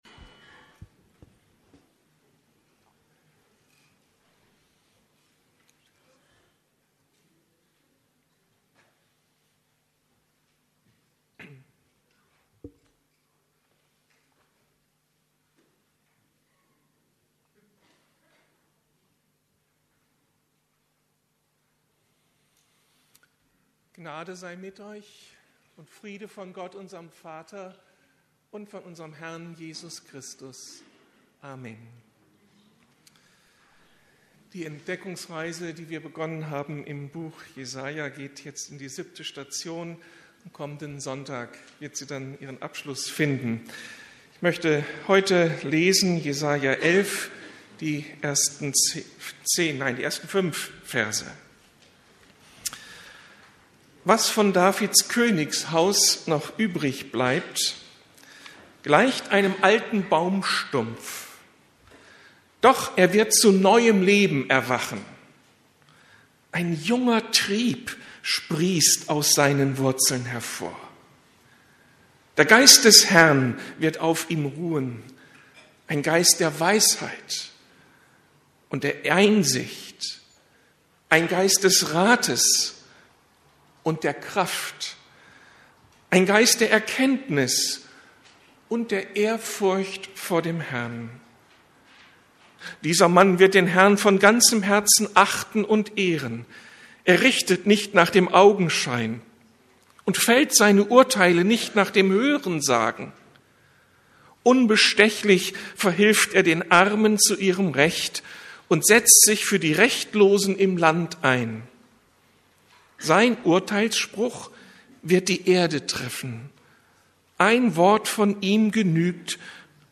Wow. Ein Gott mit dem ich rechnen kann! ~ Predigten der LUKAS GEMEINDE Podcast